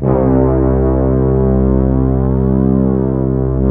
ORCHEST.C2-R.wav